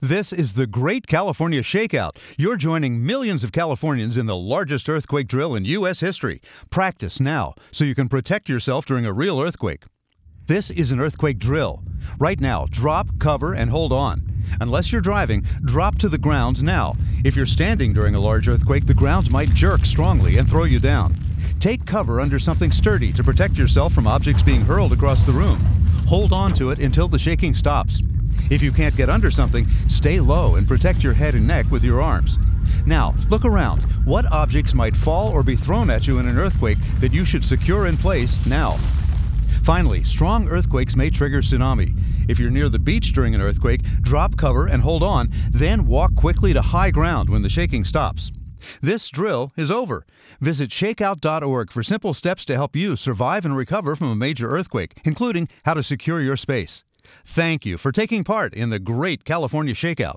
ShakeOut_60sec_Drill_Broadcast_English.mp3